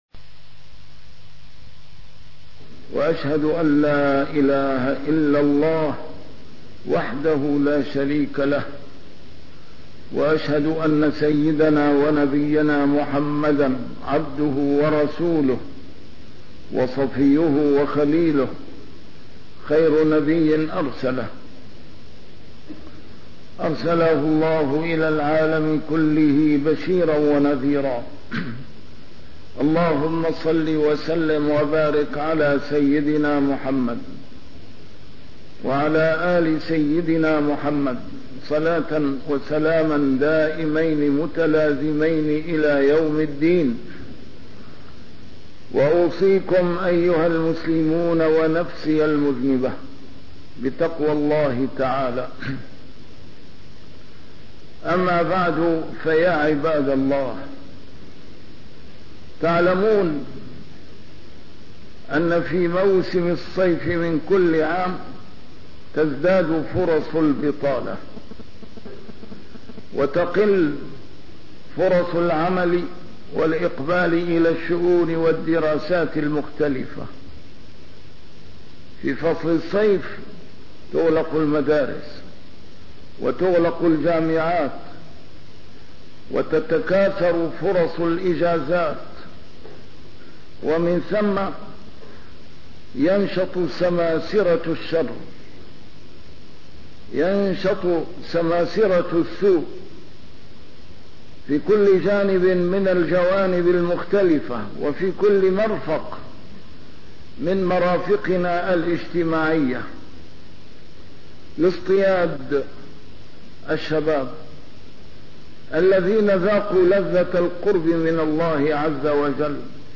A MARTYR SCHOLAR: IMAM MUHAMMAD SAEED RAMADAN AL-BOUTI - الخطب - موسم الصيف ... كيف نحصن شبابنا من سماسرة السوء والرذيلة